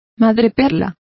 Complete with pronunciation of the translation of pearl.